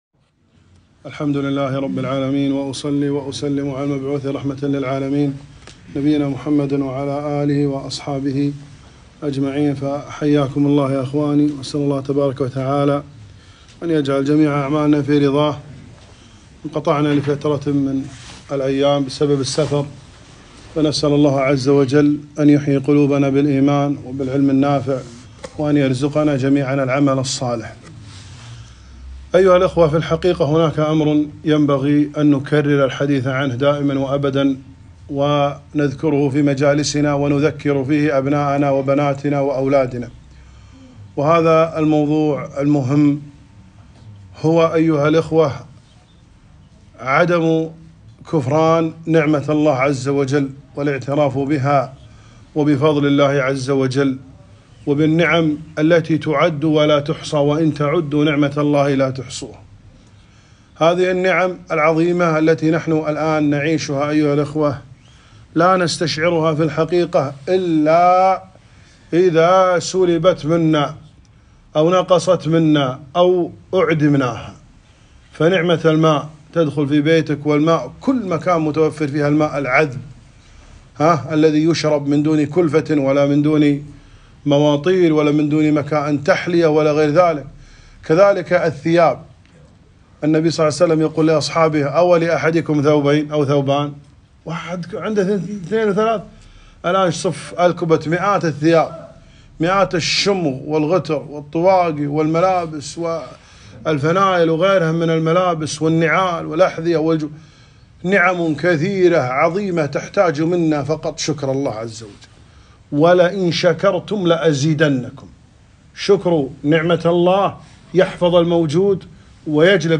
كلمة - احفظوا نعمة الله واذكروها ولا تكفروها